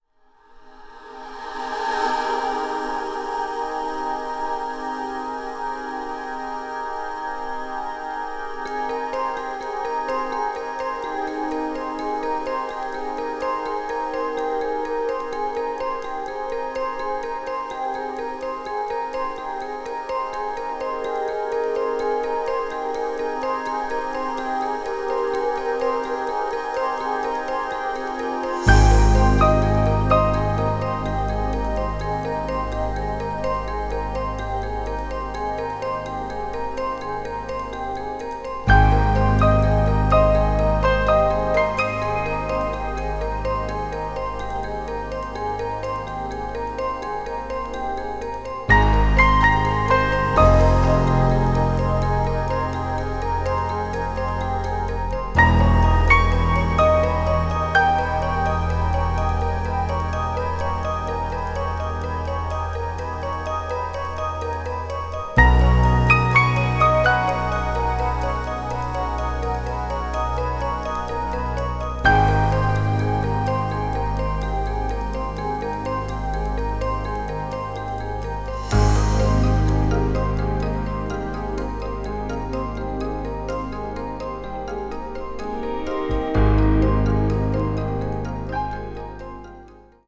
pastoral New Age fusion
crossover   electronic   fusion   new age   synthesizer